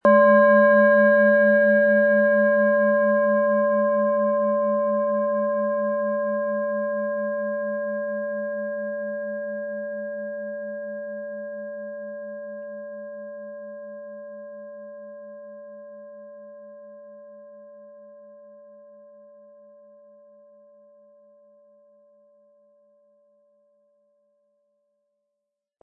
Mit ihrem ausgewogenen Durchmesser und angenehm tragbaren Gewicht erzeugt diese Schale einen sanft schwingenden, lang anhaltenden Klang, der Sie in eine Welt zwischen Bewusstem und Unbewusstem führt.
Tiefste Ton - Neptun
Mittlerer Ton – Mars
• Sanft schwingender Klang: Ideal für Meditation, Tiefenentspannung und intuitive Arbeit